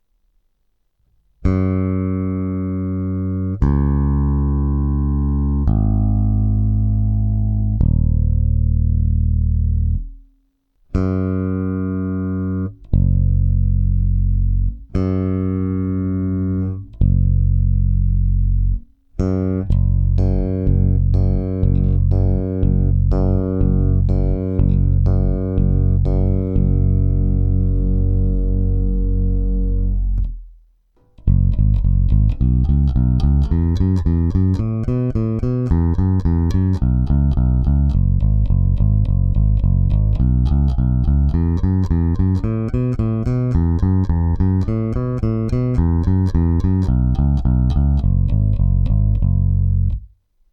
Schválně jsem si teď udělal referenční nahrávku přes můj zesilovač (TecAmp Puma 1000) s tím nastavením, jak jsem zvyklej hrát. Upozorňuju, že jsem se opravdu velmi pečlivě snažil hrát všechny struny stejnou silou.
Pravda, basa je podladěná o půl tónu (nechtělo se mi s to přelaďovat a následně hýbat se setupem).